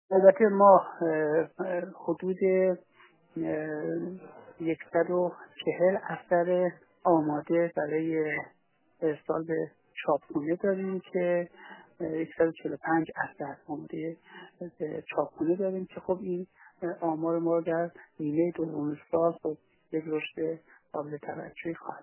در گفت‌وگو با خبرنگار ایکنا از رشد 25 تا 30 درصدی تولیدات این انتشارات در نیمه اول سال جاری خبر داد و گفت